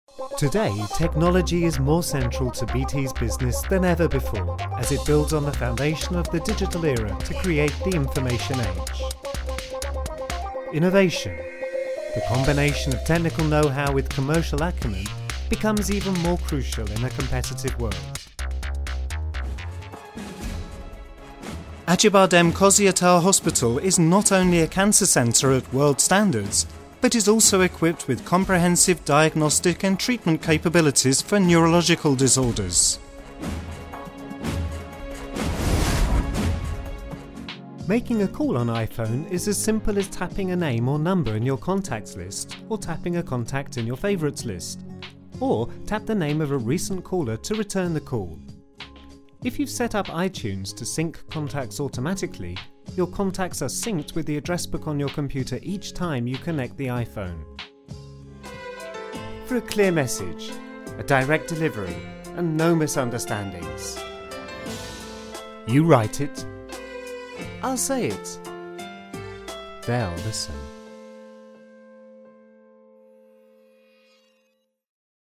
Sprechprobe: Sonstiges (Muttersprache):